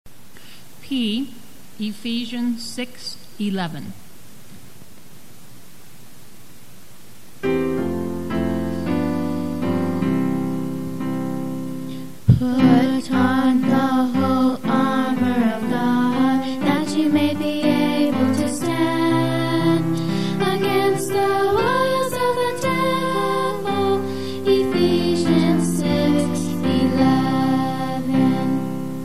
Click the next link to download the audio file of the song for each verse with lyrics, or click the third link in each box for the instrumental music alone.